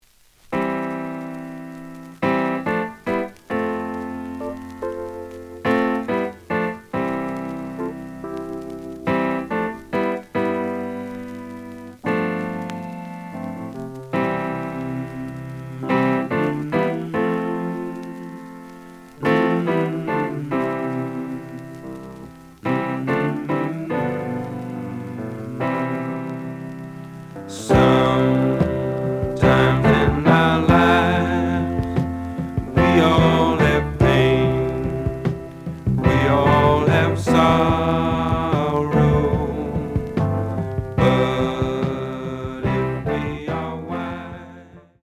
The audio sample is recorded from the actual item.
●Genre: Soul, 70's Soul
Slight noise on beginning of A side, but almost good.)